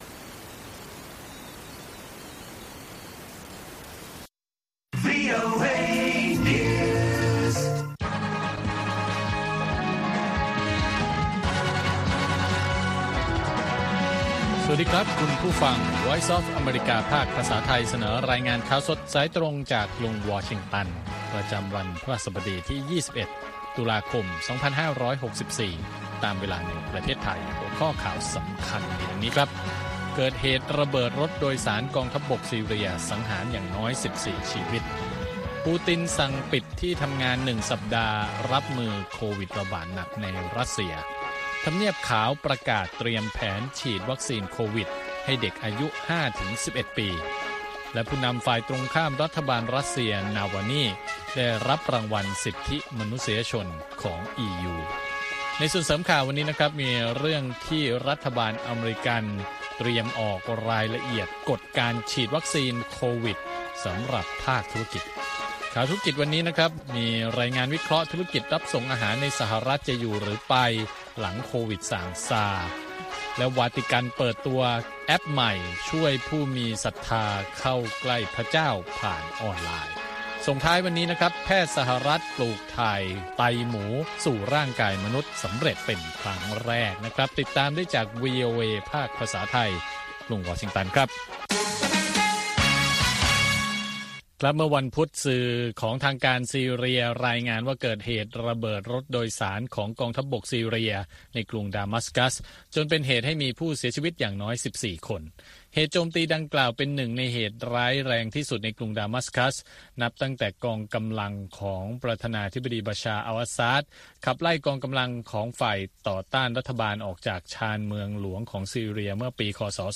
ข่าวสดสายตรงจากวีโอเอ ภาคภาษาไทย 8:30–9:00 น. ประจำวันพฤหัสบดีที่ 21 ตุลาคม 2564 ตามเวลาในประเทศไทย